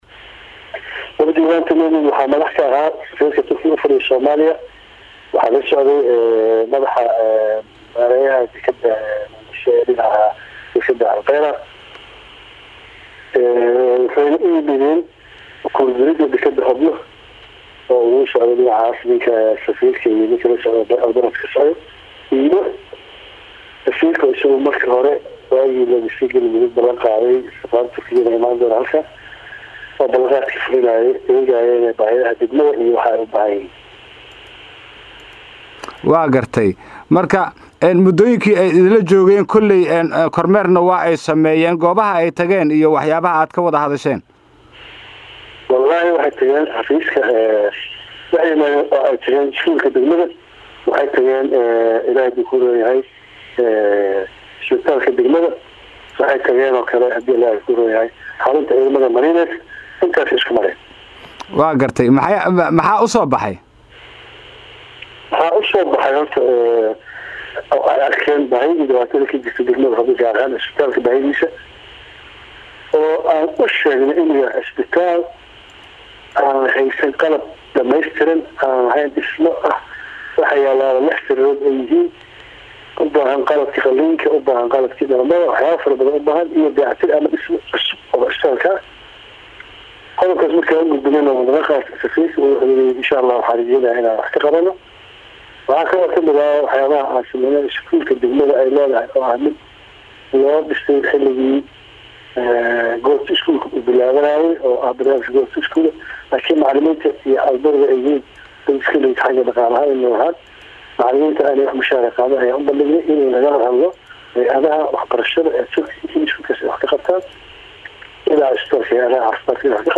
Wareysiyada